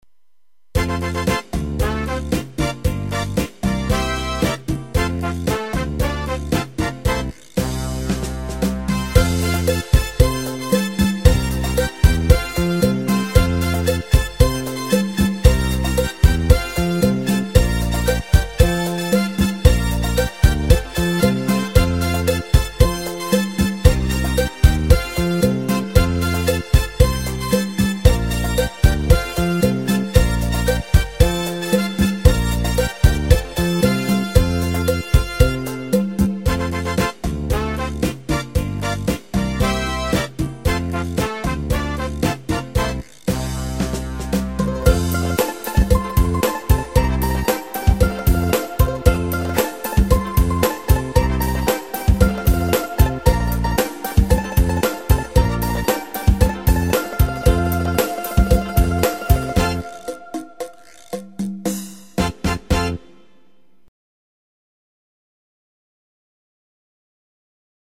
Nhạc không lời